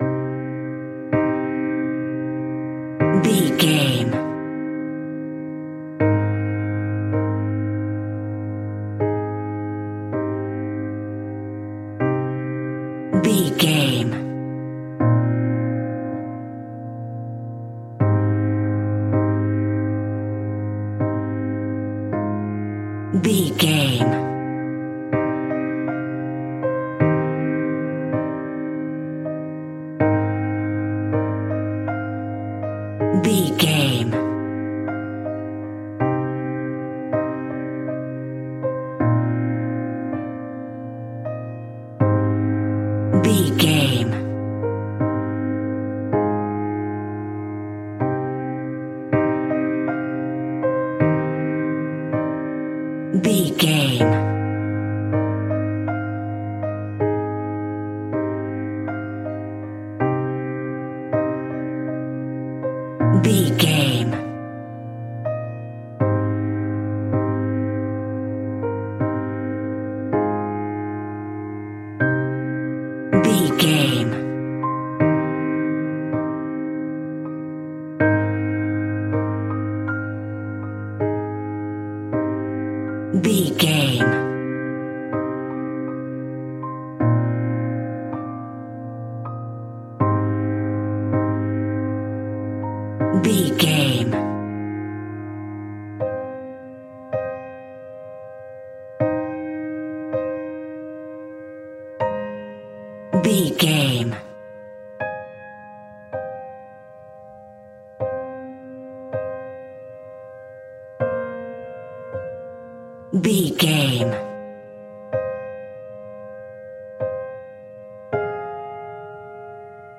Somber and reflective piano music in a minor key.
Regal and romantic, a classy piece of classical music.
Aeolian/Minor
soft